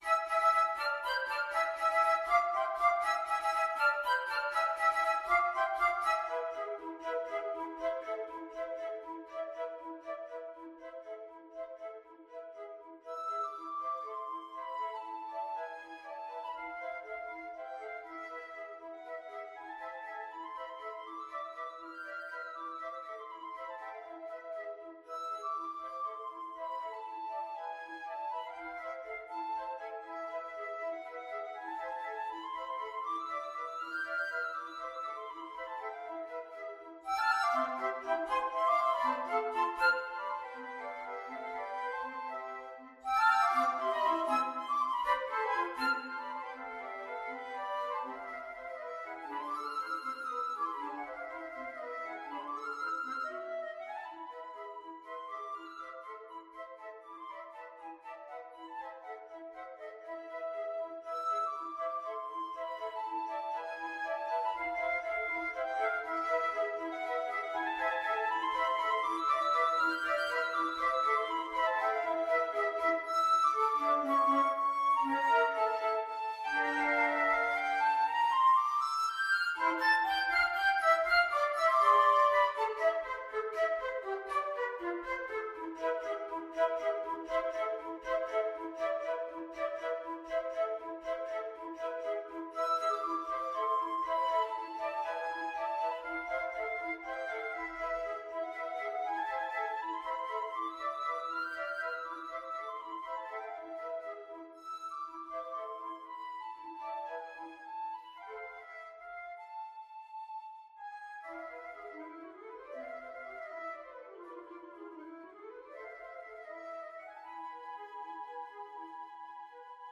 Flute 1Flute 2Flute 3
3/8 (View more 3/8 Music)
Allegro vivo (.=80) (View more music marked Allegro)
Classical (View more Classical Flute Trio Music)